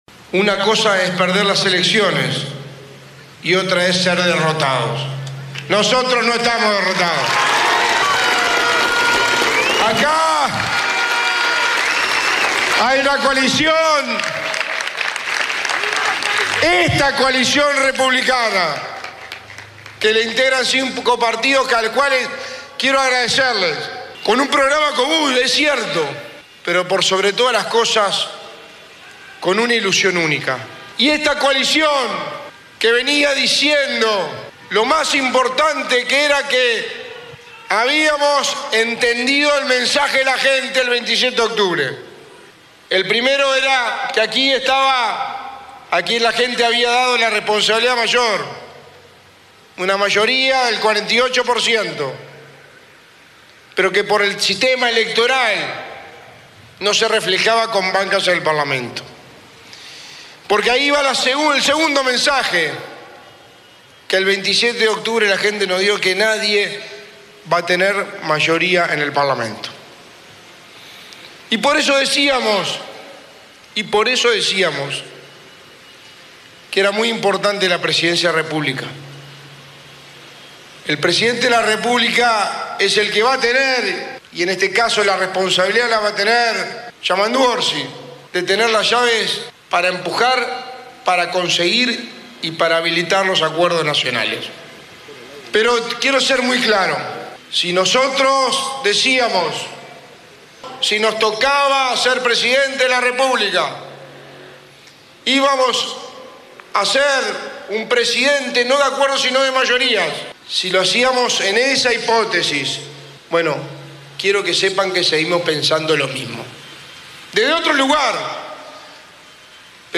Delgado-discuso.mp3